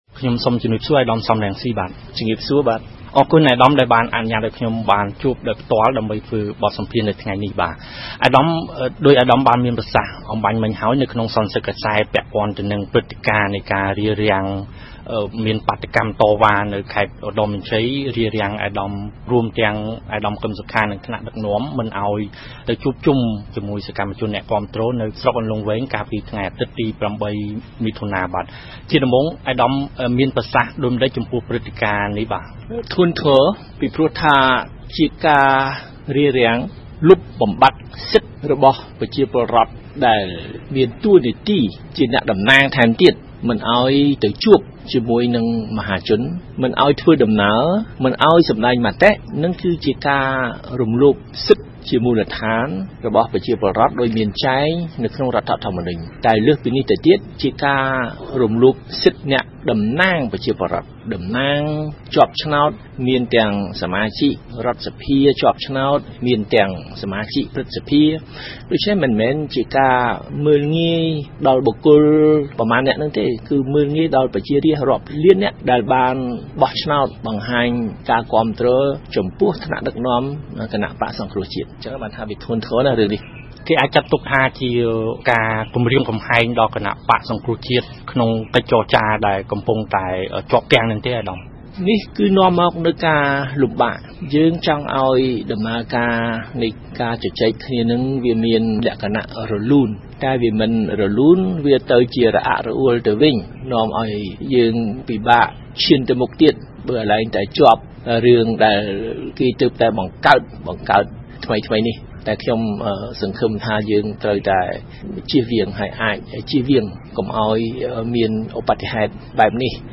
ផ្ដល់បទសម្ភាសន៍ ជាមួយ វិទ្យុបារាំង អន្តរជាតិ